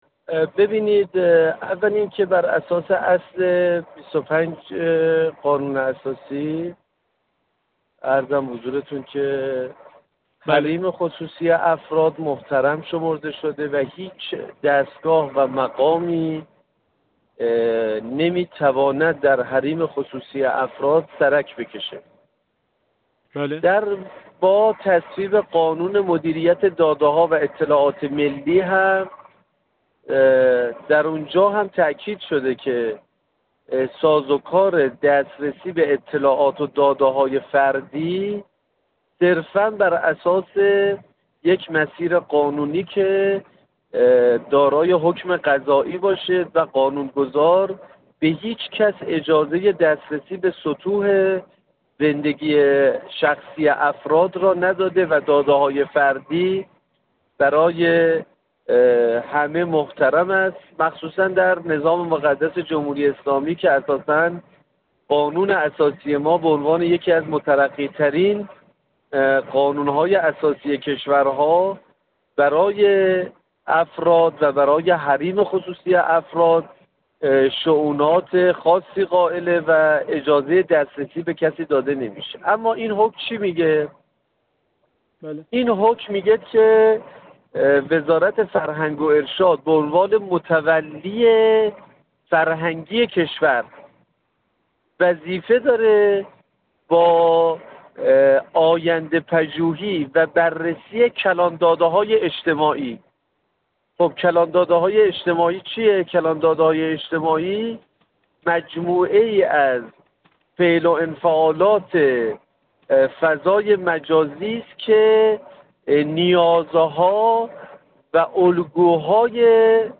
احمد راستینه، عضو کمیسیون فرهنگی مجلس
گفت‌وگو